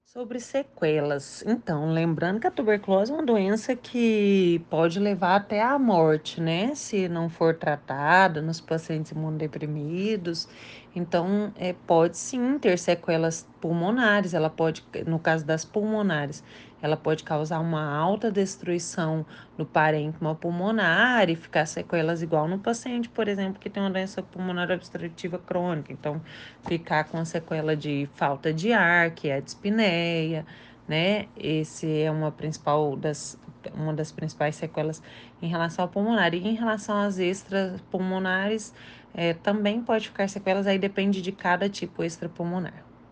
Especialista explica como se prevenir e tratar da doença que é considerada um problema de saúde pública